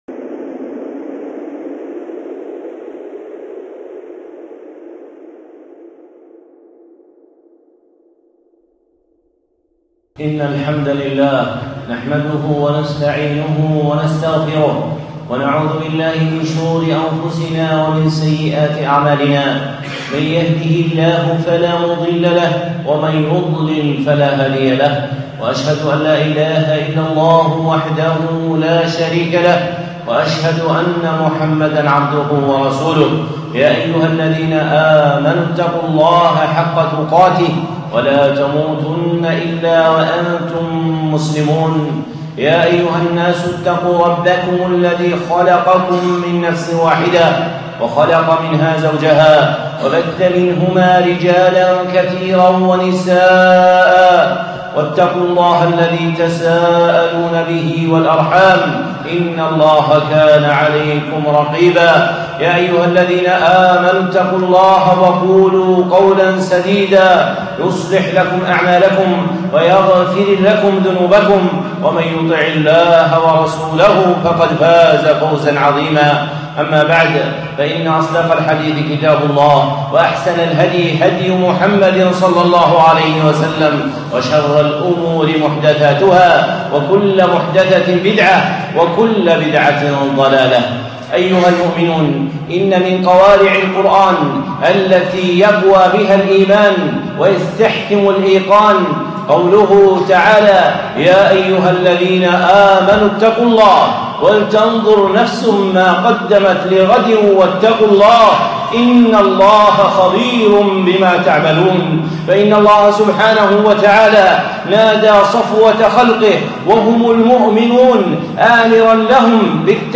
خطبة (أمران لأهل الإيمان) الشيخ صالح العصيمي